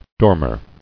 [dor·mer]